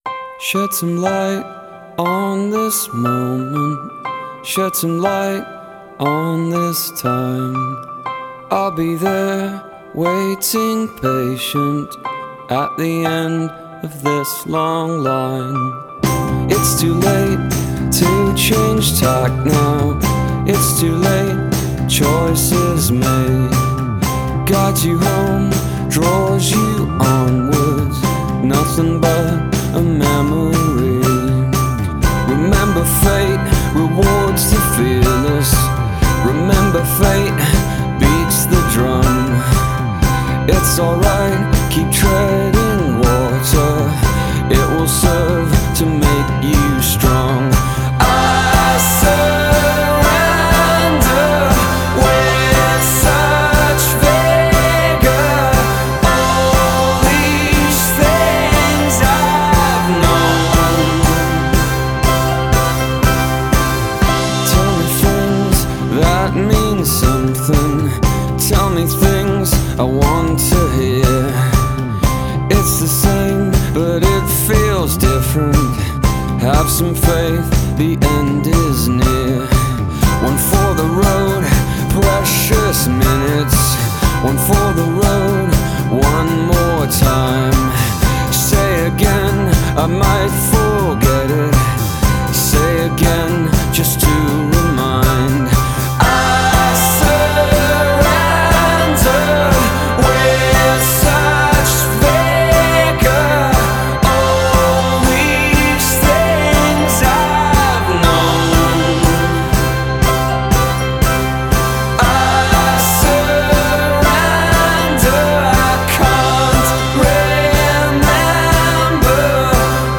专辑流派：Rock/Alternative/pop
新乐队，挺阳光明朗的POP ROCK
Drums
Keyboards